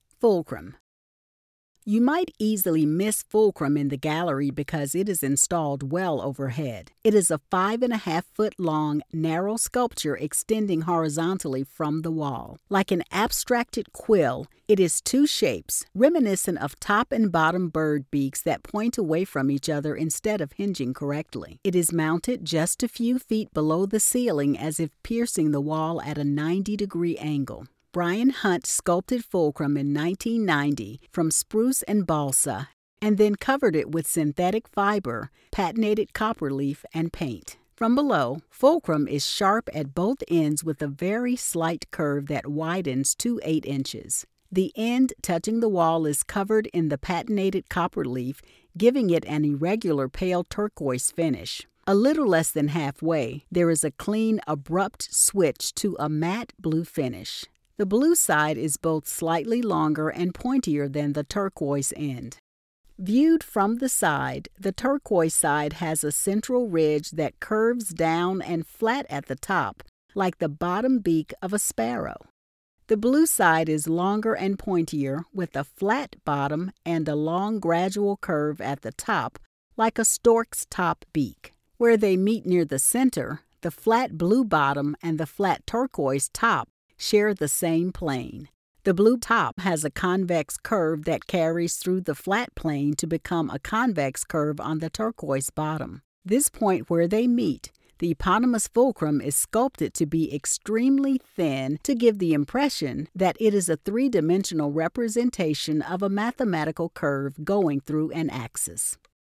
Audio Description (01:49)